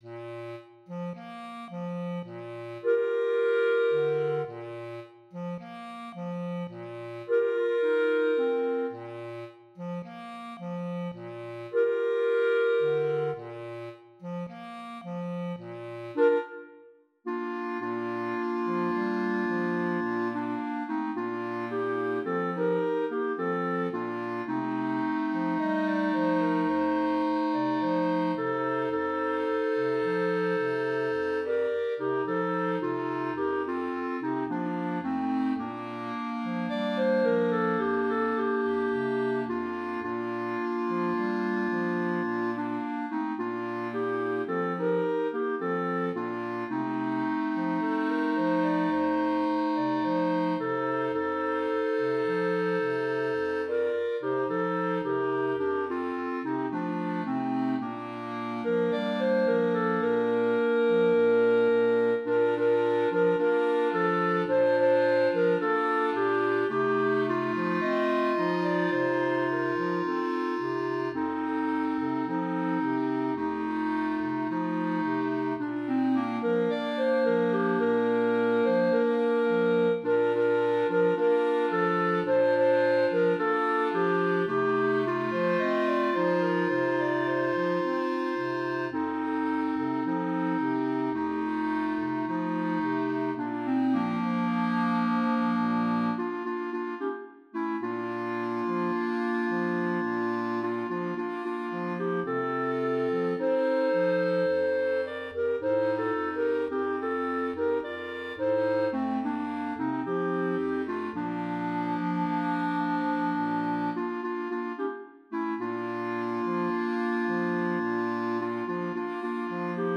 Voicing: Mixed Clarinet Quartet